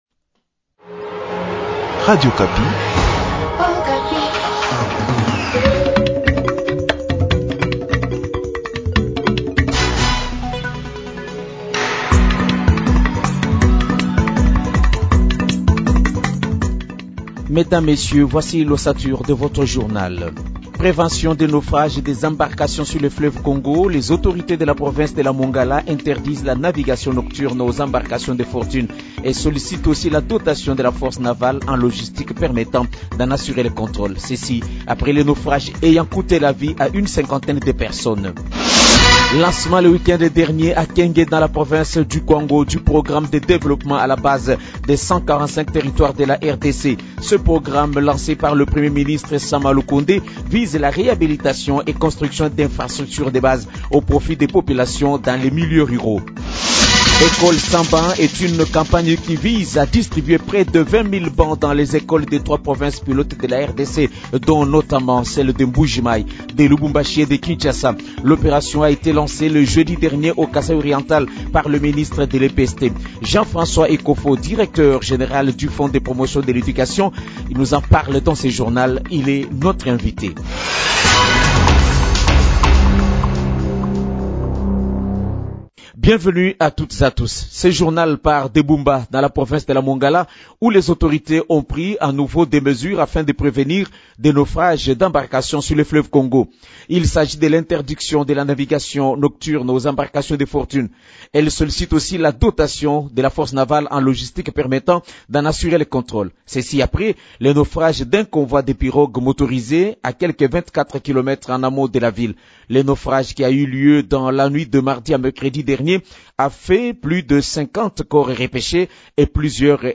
Journal matin
Journal français matin lundi 11 10